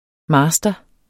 Udtale [ ˈmɑːsdʌ ]